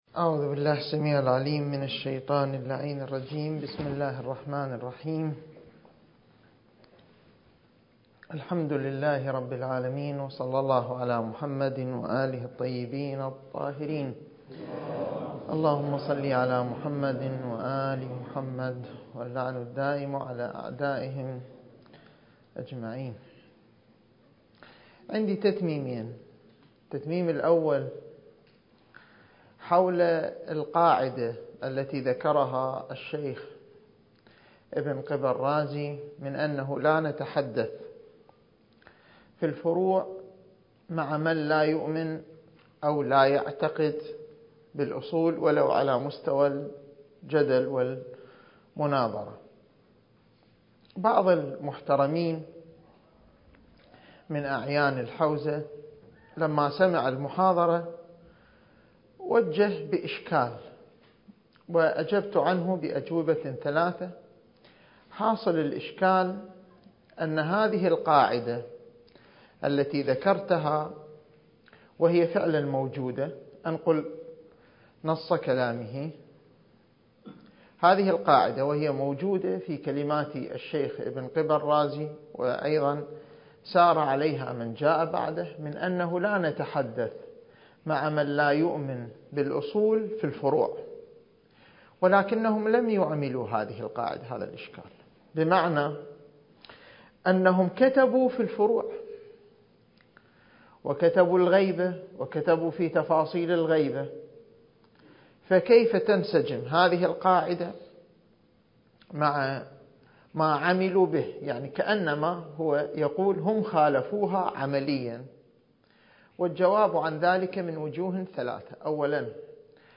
الدورة المهدوية الأولى المكثفة (المحاضرة الحادية والثلاثون)